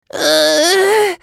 少年系ボイス～戦闘ボイス～
【ダメージ（強）2】